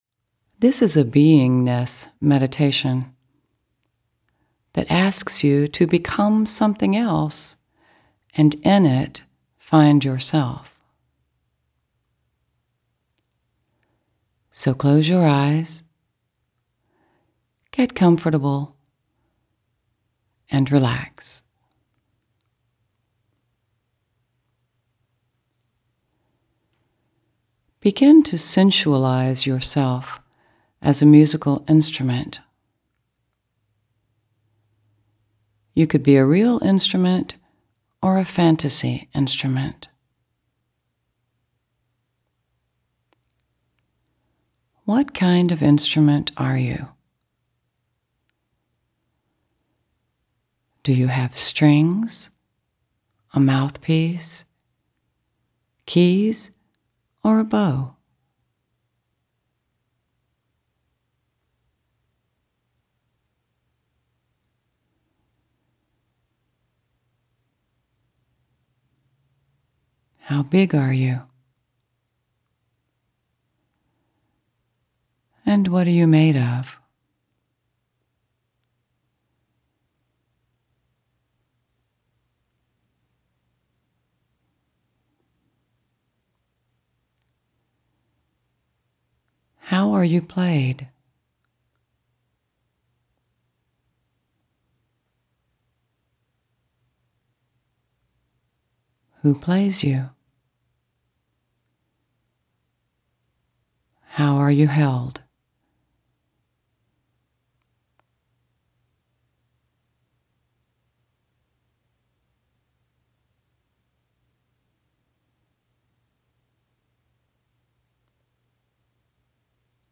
Listen to free guided meditations: